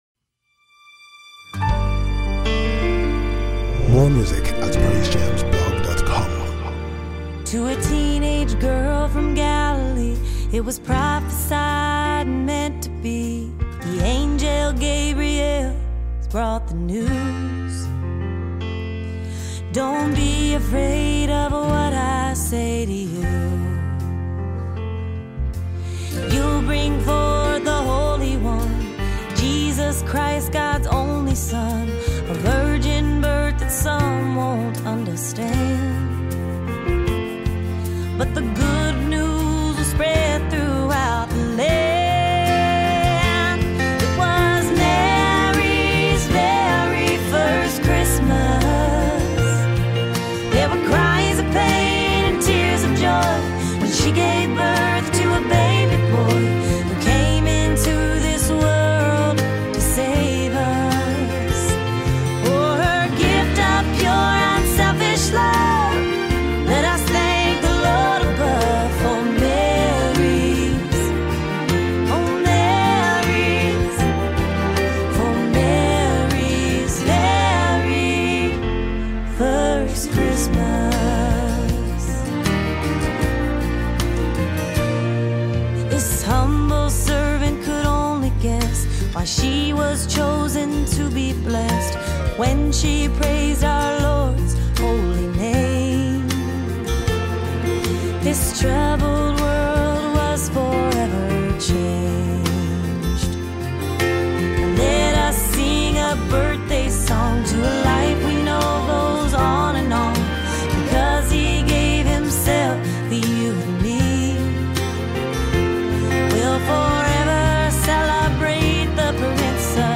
[MUSIC]
inspirational song